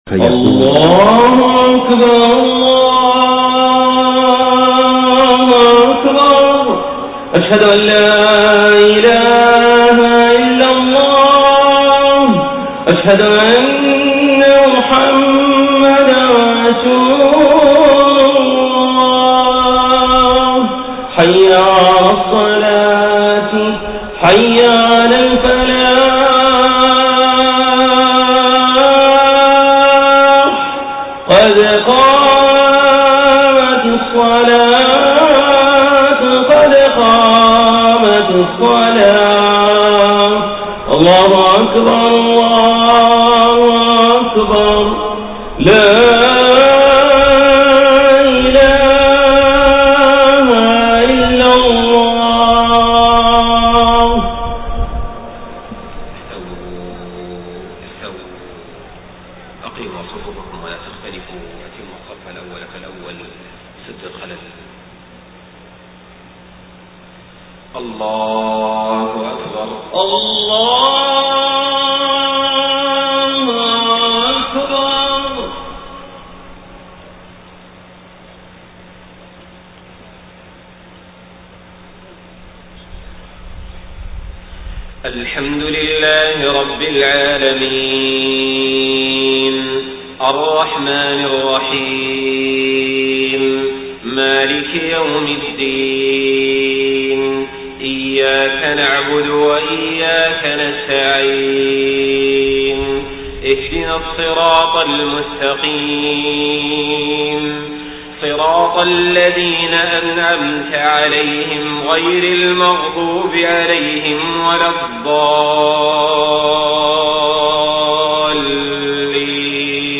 صلاة العشاء 13 ربيع الأول 1431هـ سورة الجمعة كاملة > 1431 🕋 > الفروض - تلاوات الحرمين